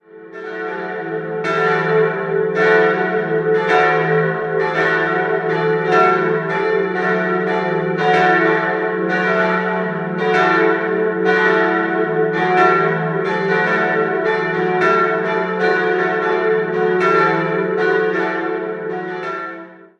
3-stimmiges Gloria-Geläute: e'-fis'-a' Die große Glocke wurde 1951 von Karl Czudnochowsky in Erding gegossen, die mittlere ist ein Werk der Gießerei Perner von 2003 und ersetzt die beim Turmeinsturz beschädigte Glocke. Aus dem Jahr 1927 stammt noch die kleine Glocke.